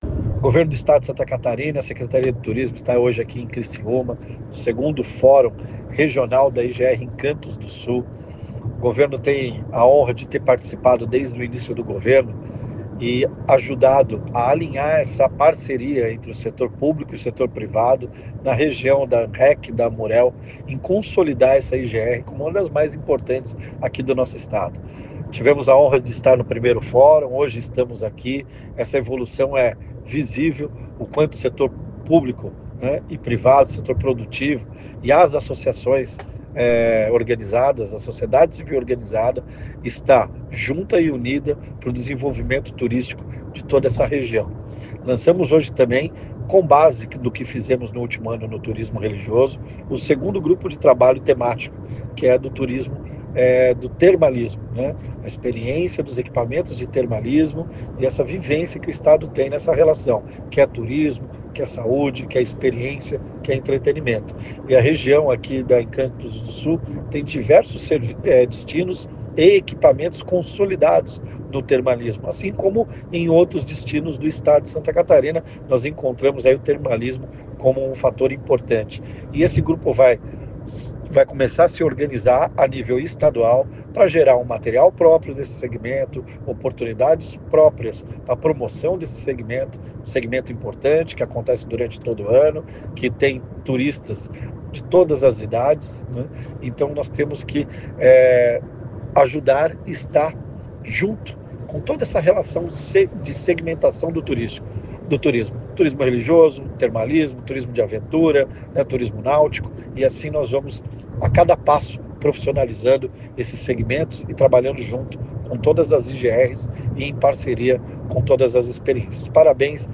A Secretaria de Turismo (Setur/SC) participou nesta terça-feira, 5, do 2º Fórum Regional do Turismo, realizado no Teatro Elias Angeloni, em Criciúma.
Para o Secretário de Turismo, a realização da 2ª edição do Fórum reforça a importância da organização e alinhamento para o fortalecer as IGRs: